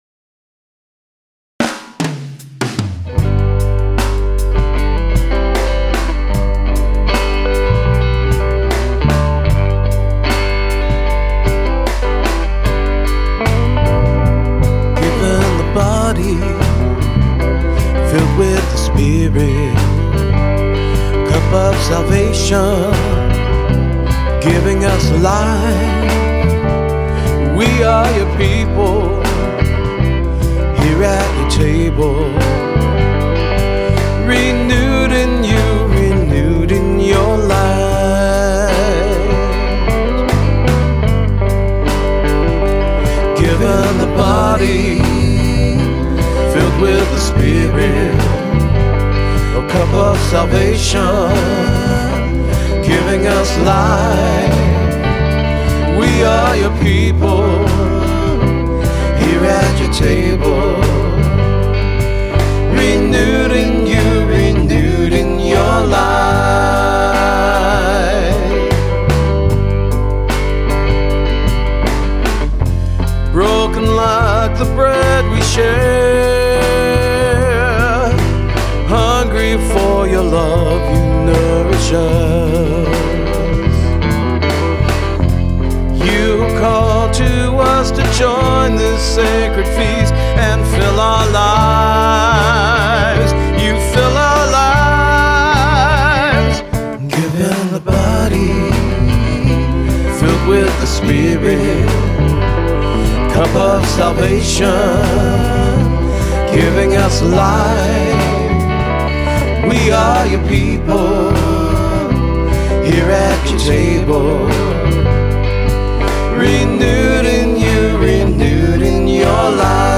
🙂 And having played at church for over 30 years, writing music for worship just kind of came naturally. Here’s a song I wrote called Given the Body. It features my Strat on the right, and my Les Paul on the left; both played through my Aracom PLX BB 18.